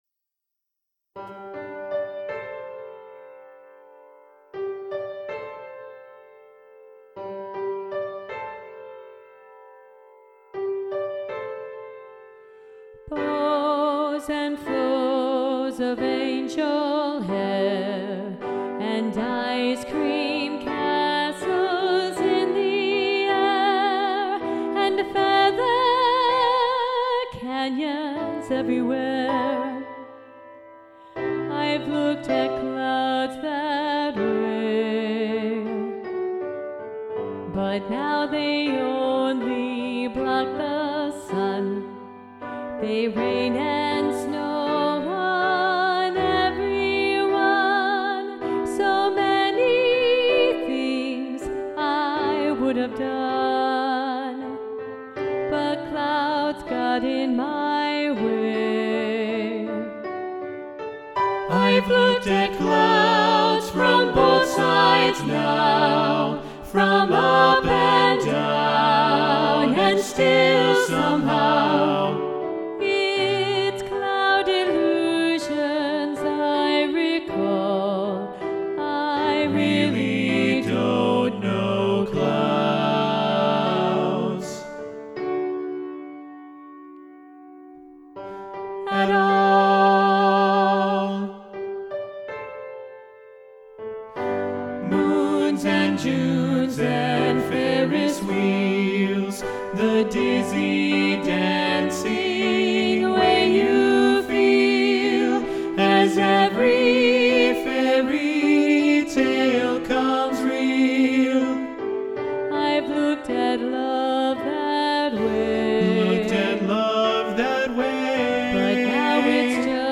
Alto 1 Muted
Both-Sides-Now-SATB-Alto-1-Muted-arr.-Roger-Emerson.mp3